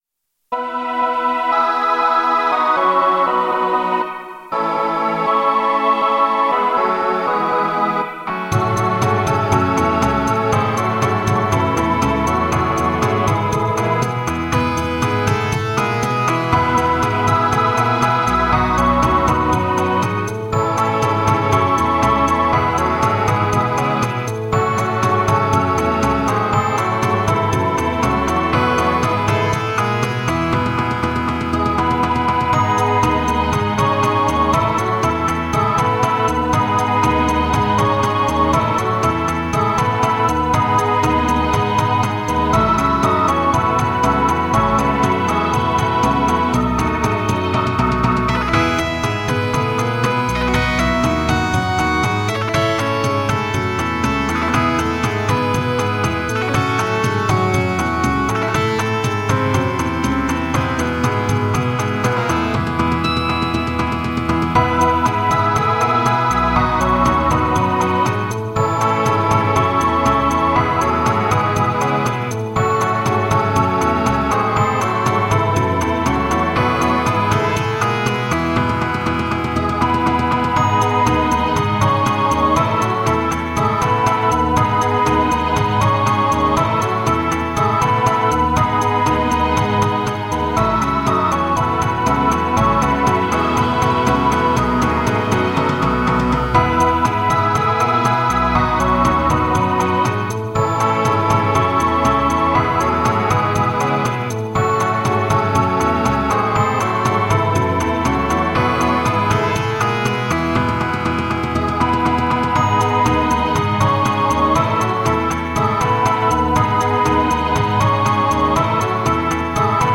Спокойная приятная мелодия.